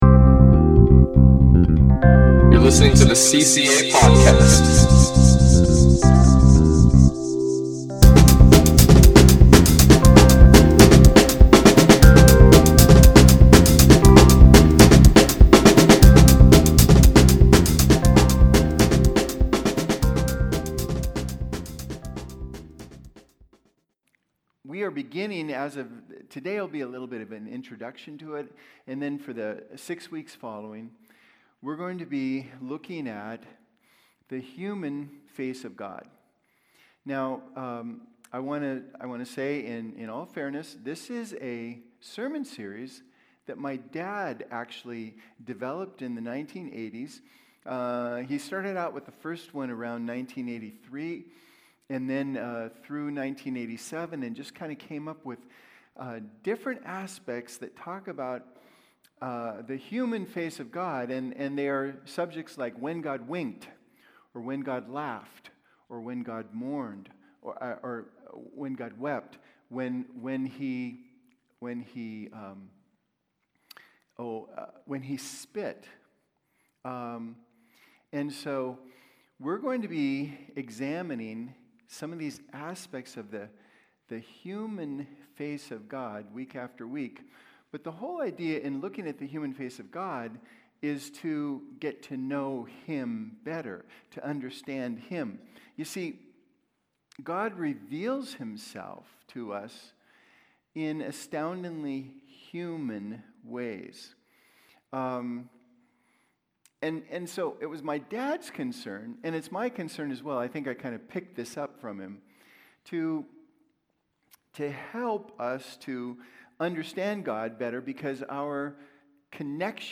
Listen to Message | Download Notes